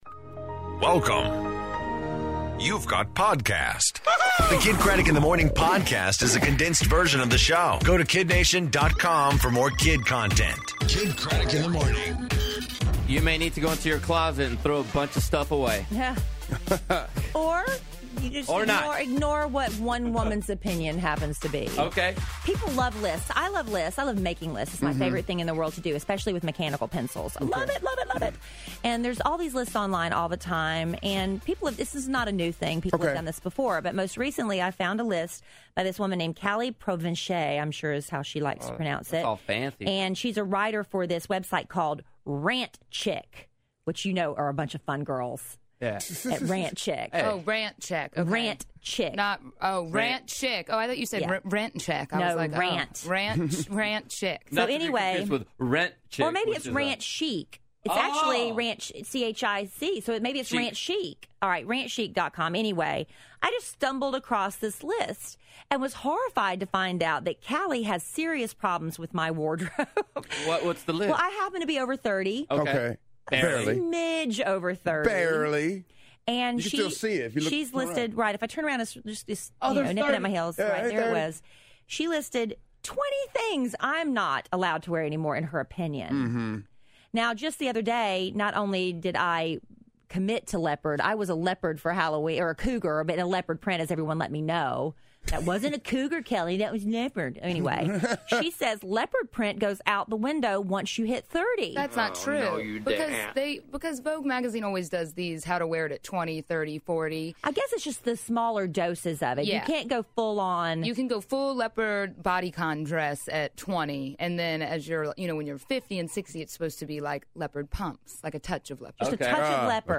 Kendrick Lamar In Studio! Plus, Free Money Name Game And What To Wear